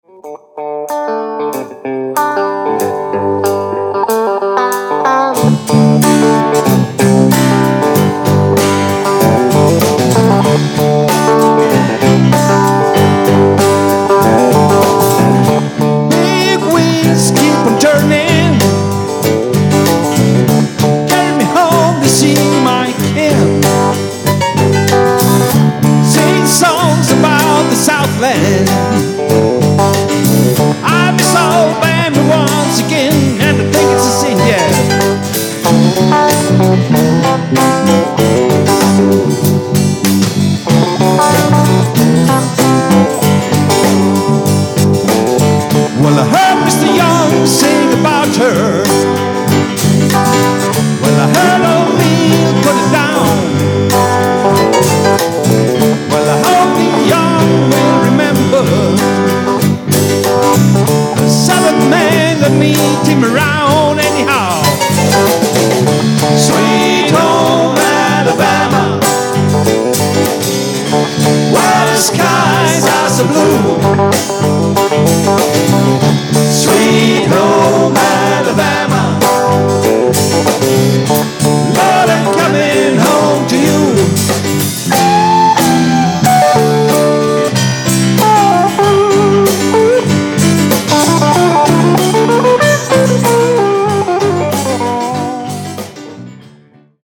absolut Livemusic